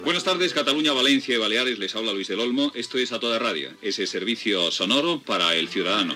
Identificació del programa
Info-entreteniment